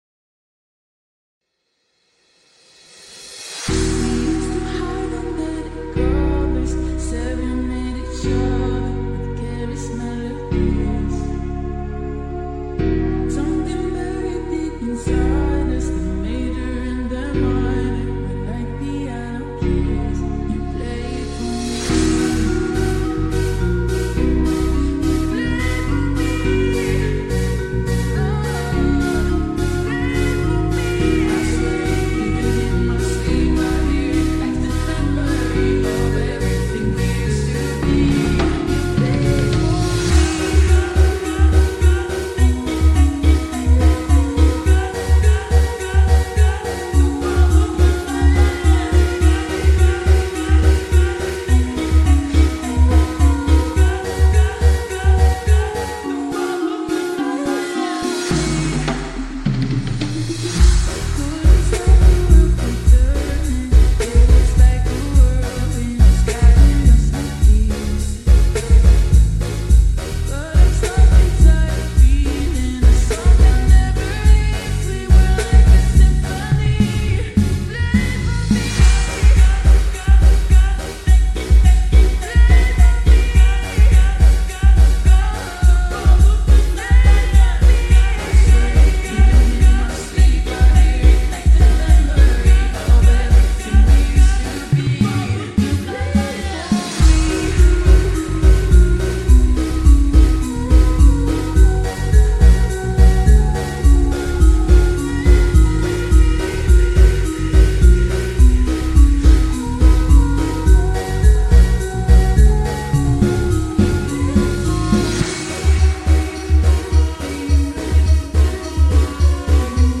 BREAKBEAT REMIX
SLOWED VERSION